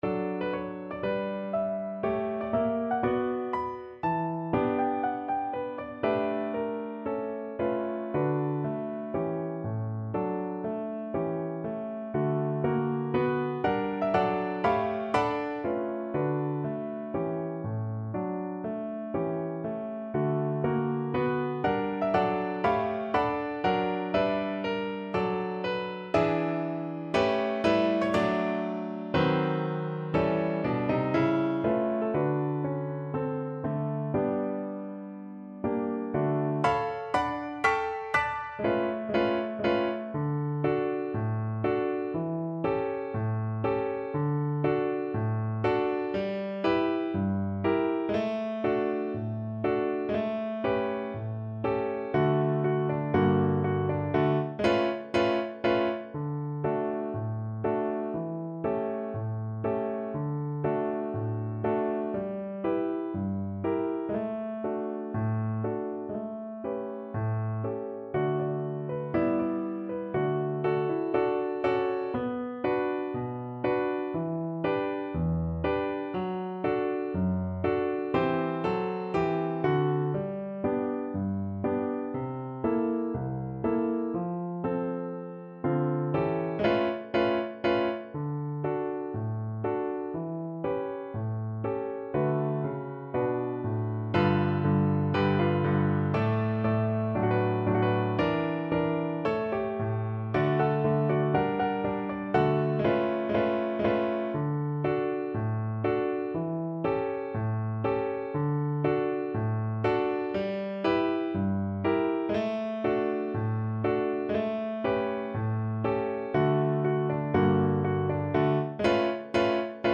~ = 120 Moderato